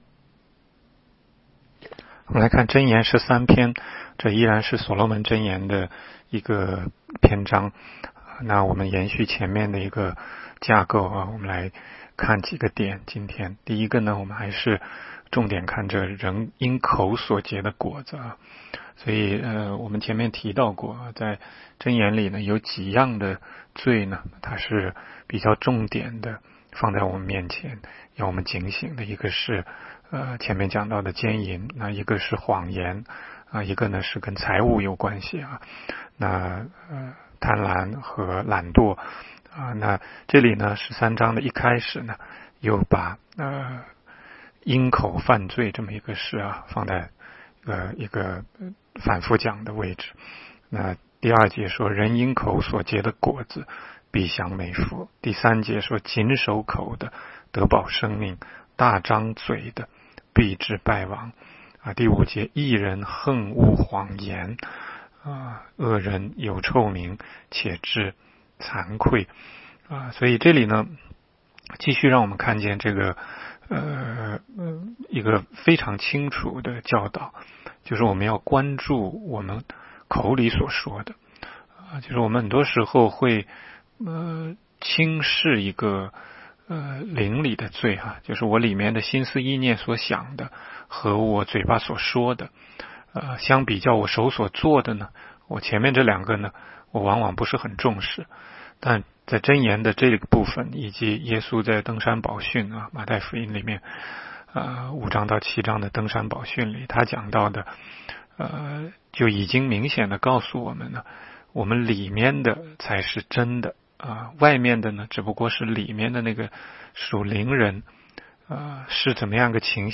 16街讲道录音 - 每日读经 -《 箴言》13章